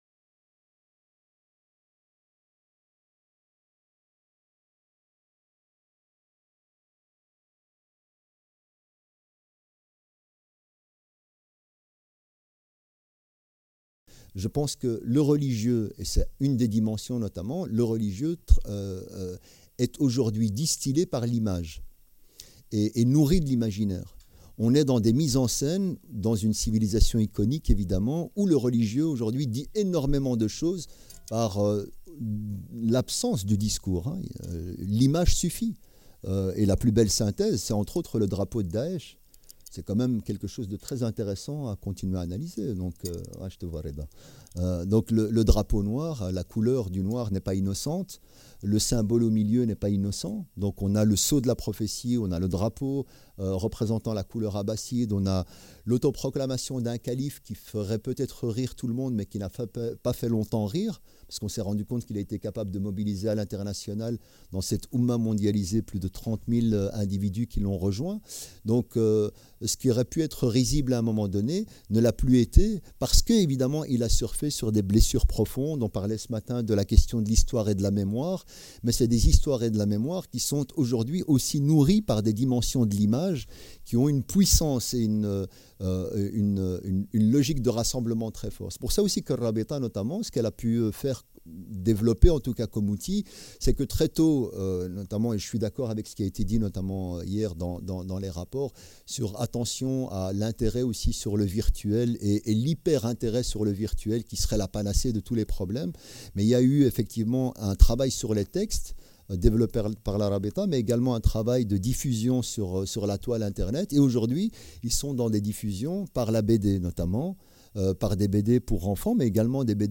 Les religions au défi de la non-violence - Colloque international Rabat 2019 | Canal U